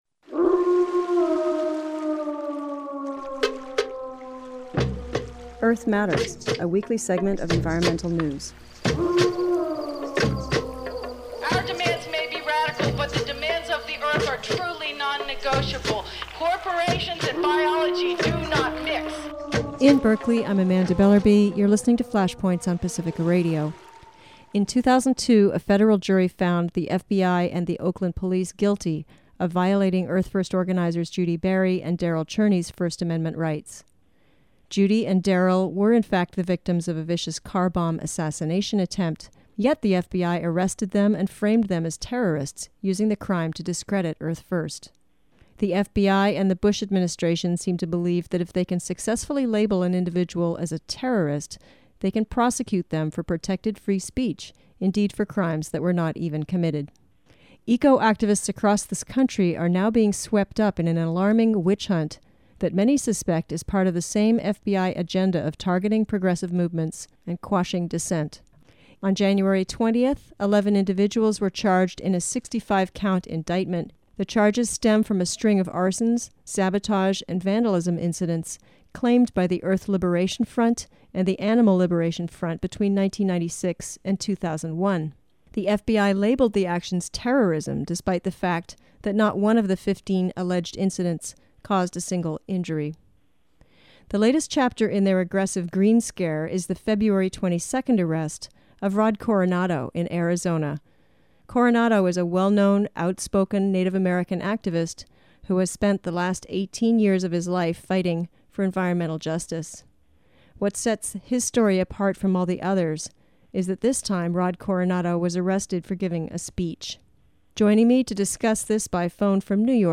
EARTH MATTERS RADIO - Interview